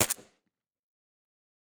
pistol_4.ogg